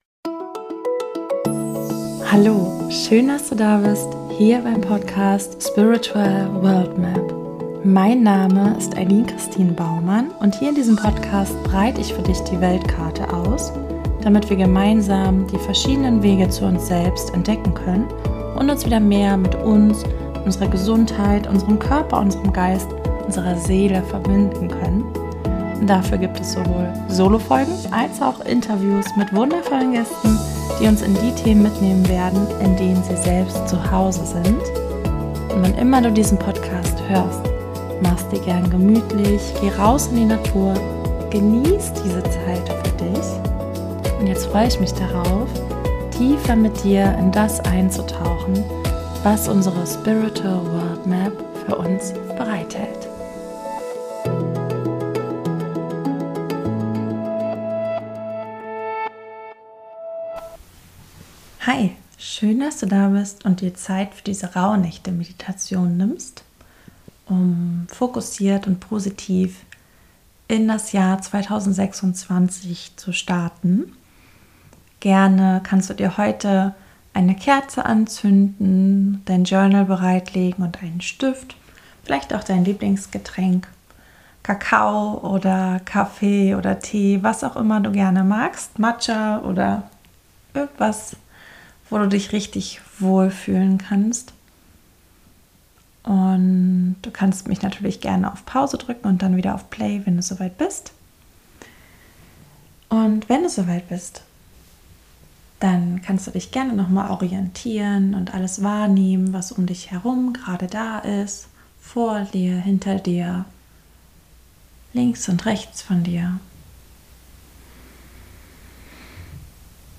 In der heutigen Podcast Folge erwartet dich eine geführte Meditation um fokussiert und positiv ins das neue Jahr zu starten. Mach es dir mit deinem Wohlfühl-Getränk gemütlich, zünde dir eine Kerze an und nimm dir gern dein Journal oder Notizbuch zur Hand, um deine Impulse aufschreiben zu können.